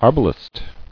[ar·ba·lest]